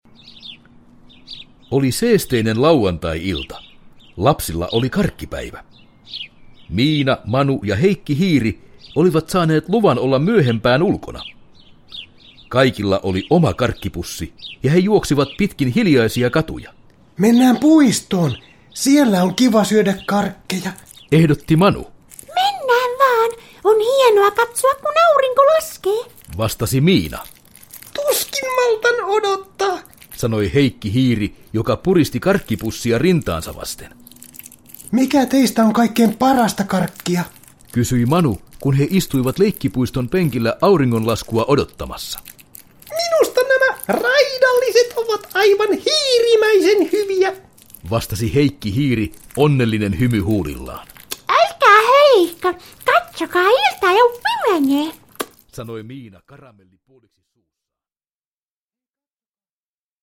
Miina ja Manu Salaperäinen lentäjä – Ljudbok – Laddas ner